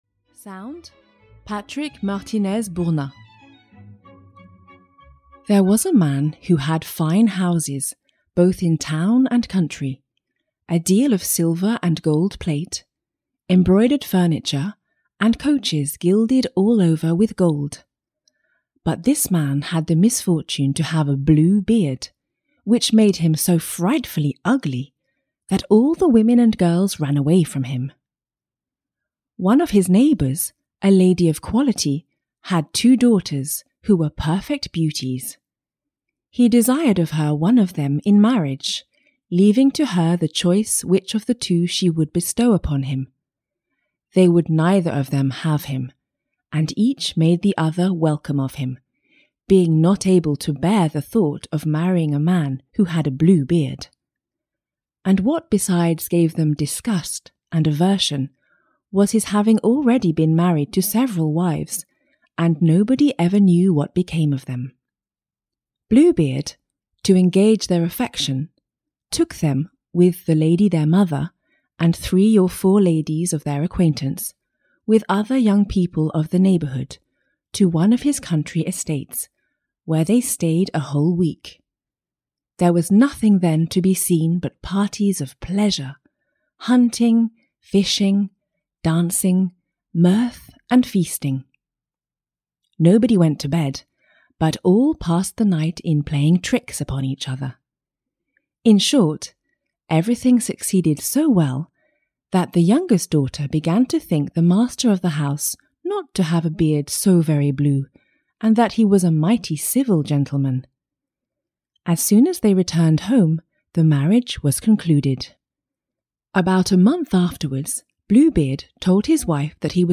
Audio knihaTop 10 Best Fairy Tales (EN)